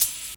Boom-Bap Hat CL 62.wav